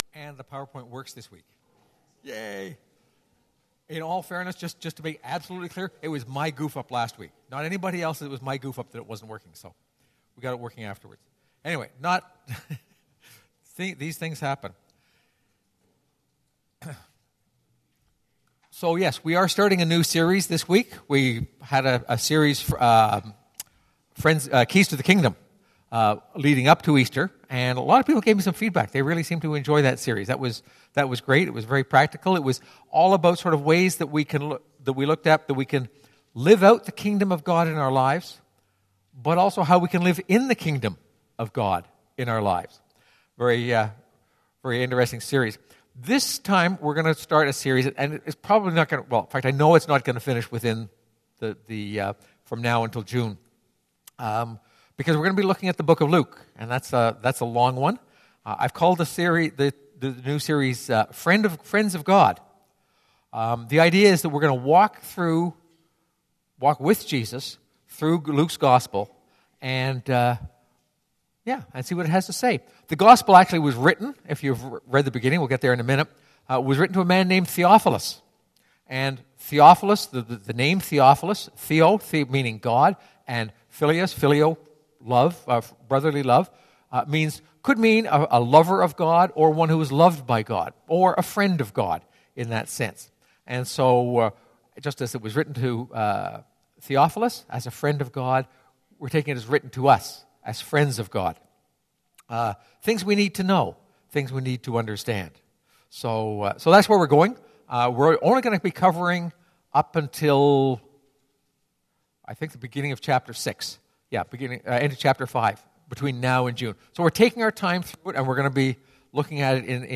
This sermon is based on Luke 1:1-4